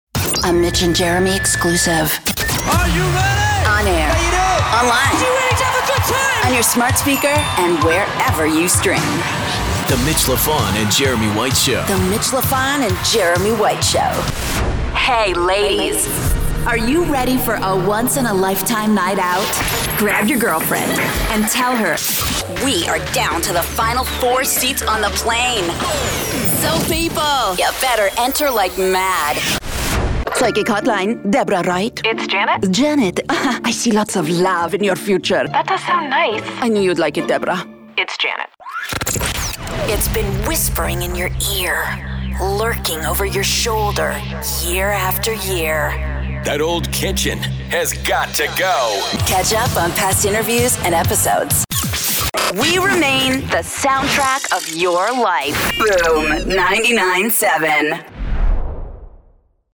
Imágenes de radio
Tengo mi propio estudio con equipos de última generación;
DAW - Protools, Mic - Sennheiser MKH 416, Preamp - Universal Audio 6176, Interfaz - Volt 176.
Mediana edad